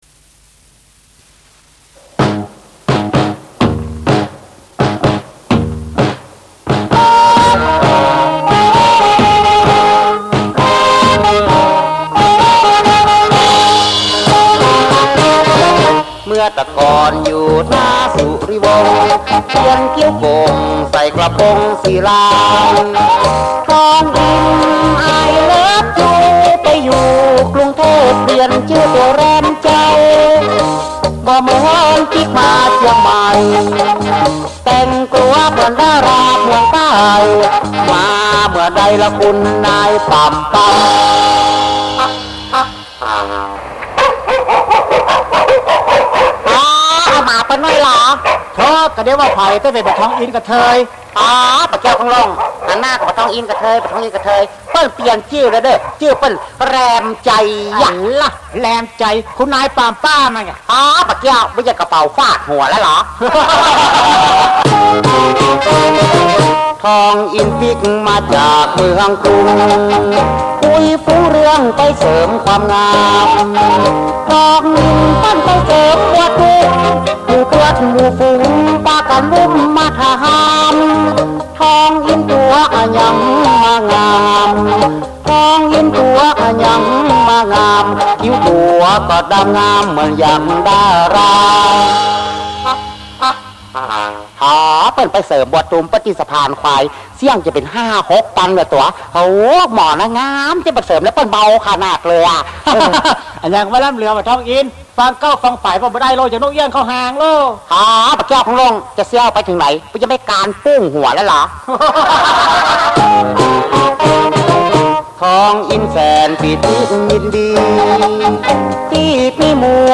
เพลงคำเมือง